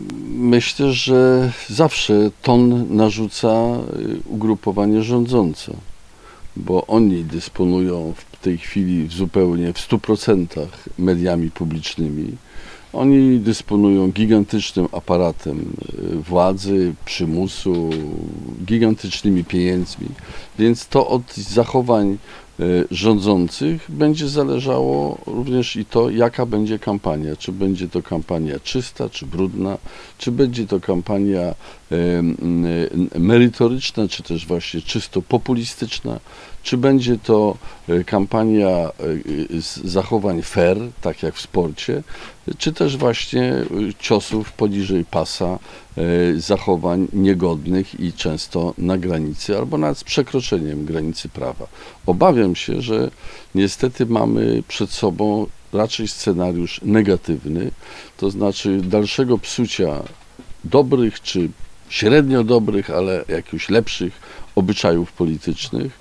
Były prezydent w wywiadzie, jakiego udzielił Radiu 5, mówił o swoich przemyśleniach na temat aktualnej, przedwyborczej sytuacji politycznej w kraju.